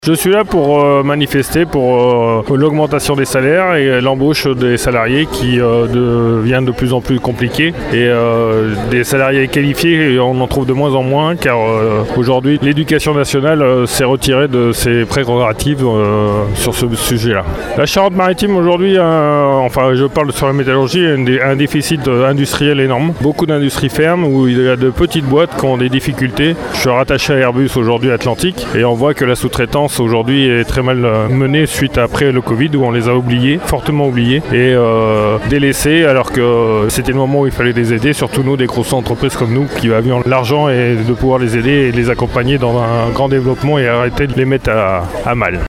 Manifestation hier à Rochefort.
manif-rft-2.mp3